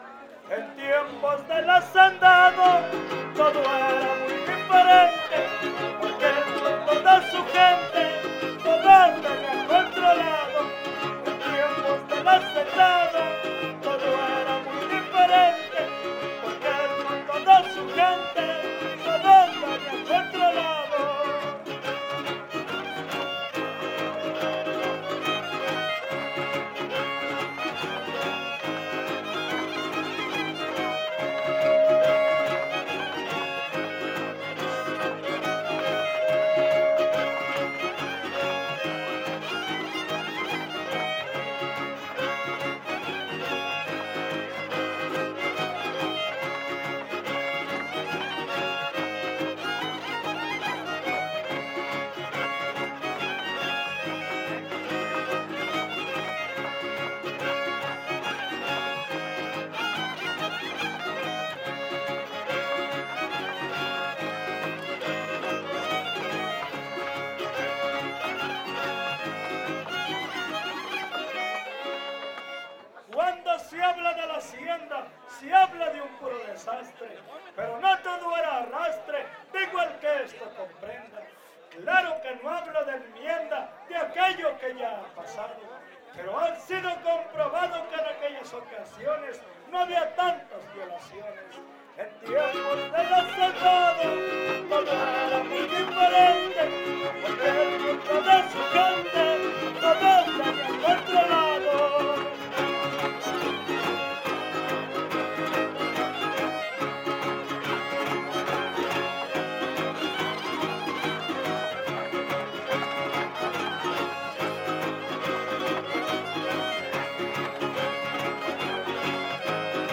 Poesía popular Décima Huapango arribeño
Vihuela Guitarra Violín
Topada ejidal: Cárdenas, San Luis Potosí